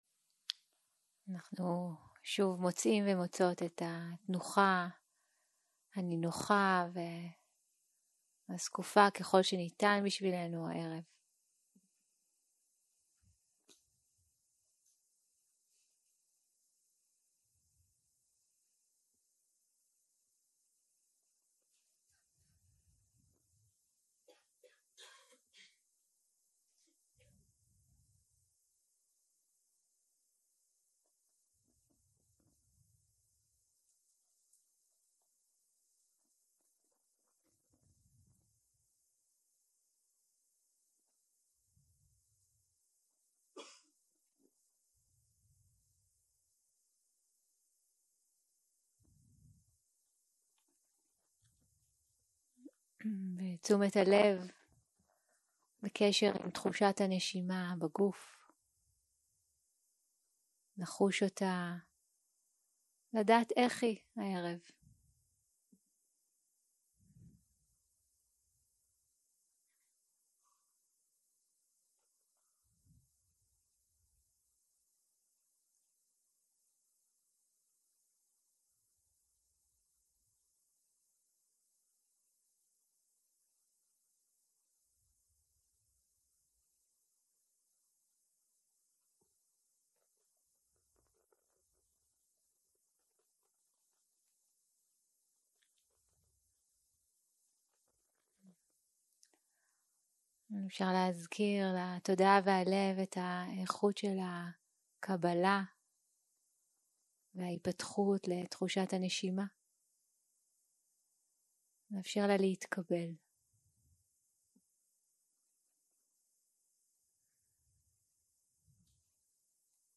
יום 2 - ערב - מדיטציה מונחית - הדרשה במעון הנזירות - תשומת לב לגוף - הקלטה 5 0:00 0:00 סוג ההקלטה: Dharma type: Guided meditation שפת ההקלטה: Dharma talk language: Hebrew